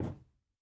added stepping sounds
Parquet_Floor_Mono_01.wav